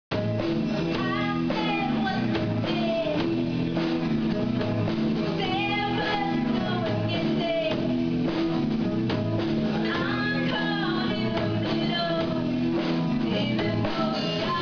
WAV Snippets, Live from gigs: